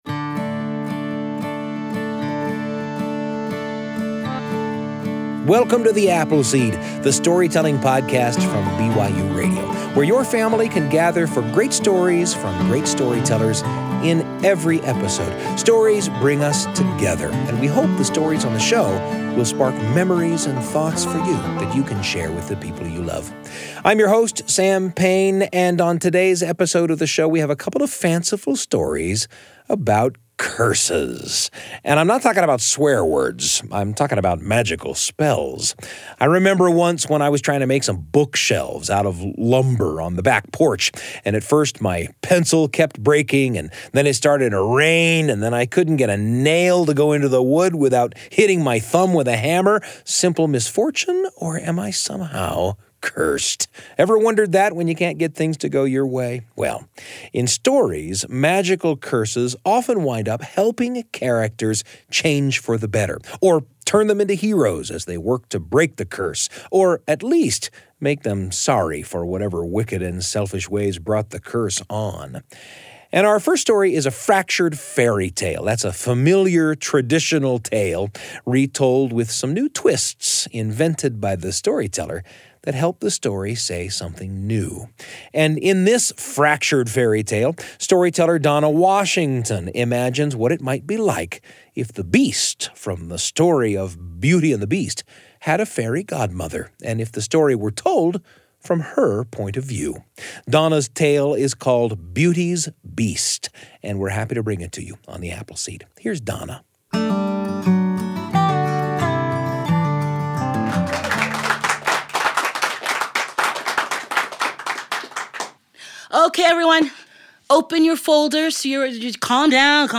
Key Words: Stories, Storytelling, Storyteller, Family, Fractured Fairytale, Love, Perspective, Curses, Heroes, Trees, Roses, Failure, Chance, Healing, Fairy Godmother, Beauty and the Beast, Wishes